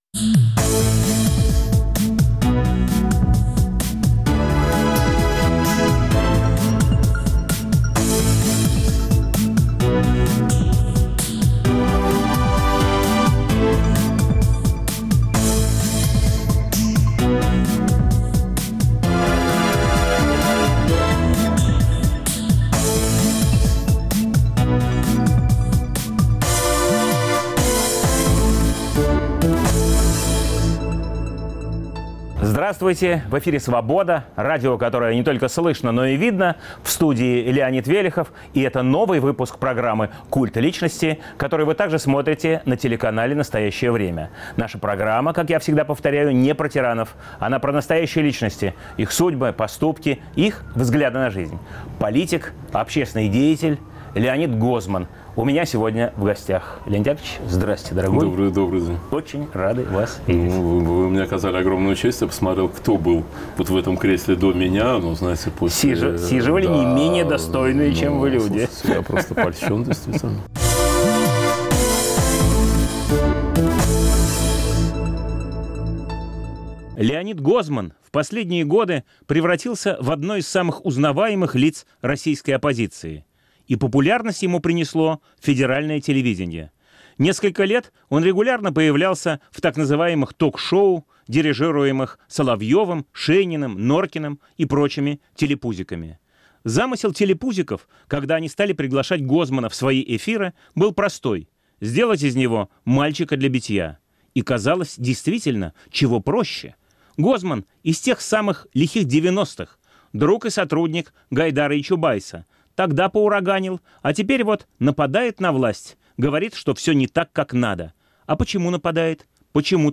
В студии нового выпуска "Культа личности" политик-оппозиционер Леонид Гозман.